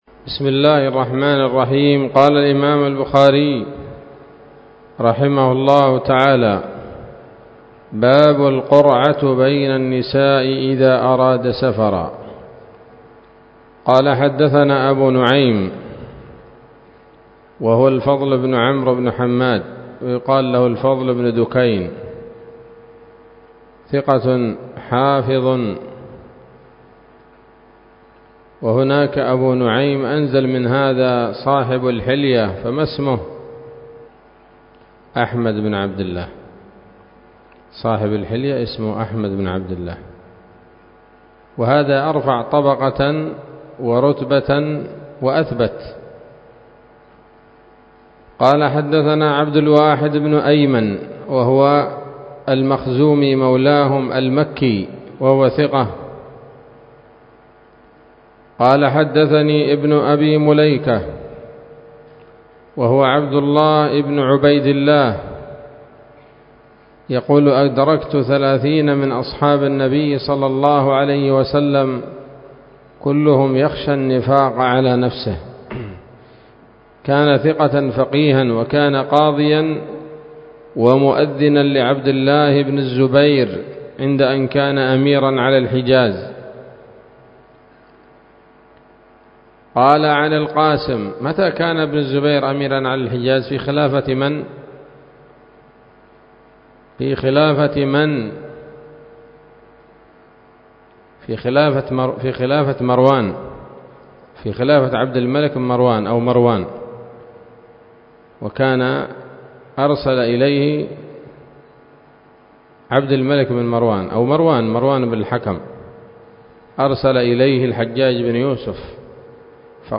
الدرس التاسع والسبعون من كتاب النكاح من صحيح الإمام البخاري